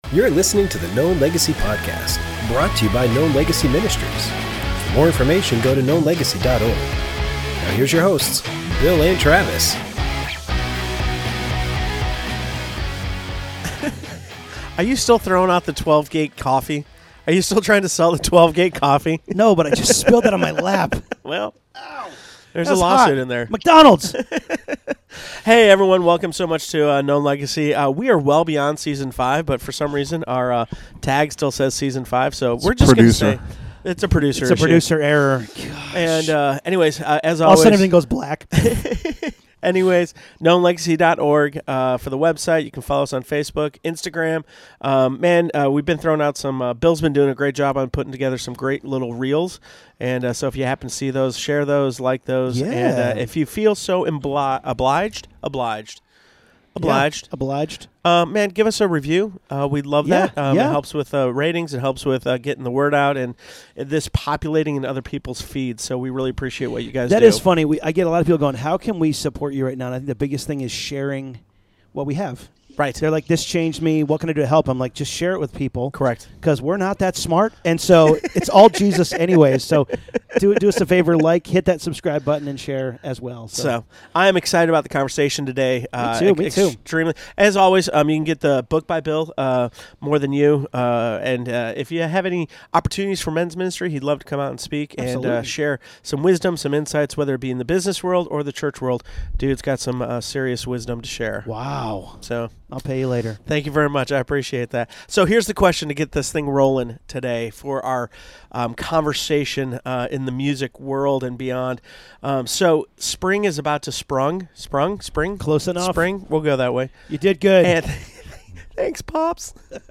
Love is everything we need - Interview